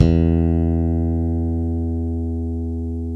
Index of /90_sSampleCDs/Roland L-CD701/BS _Jazz Bass/BS _Jazz Basses